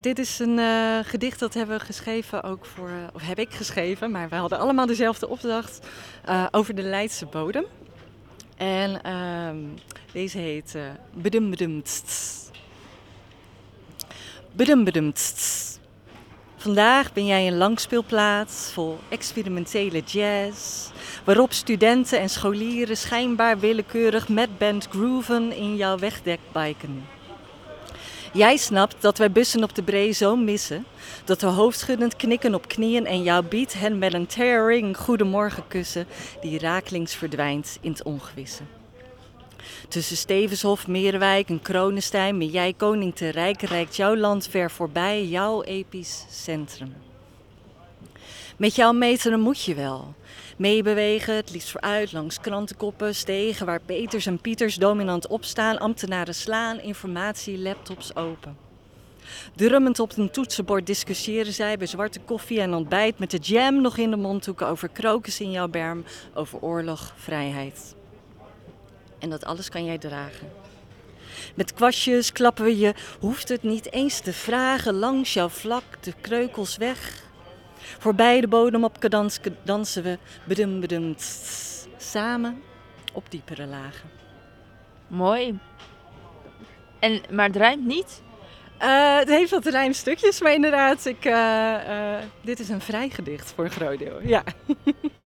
draagt één van haar gedichten voor.